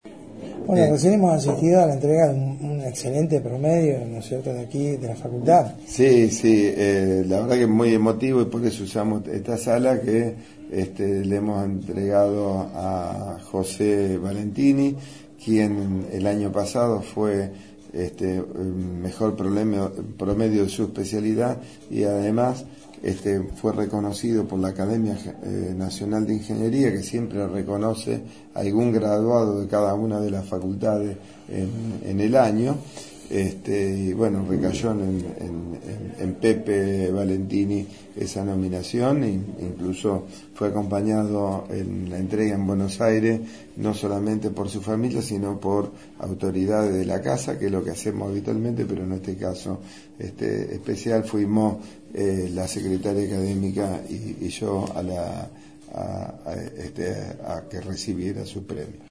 Audio: Charla con el Ing.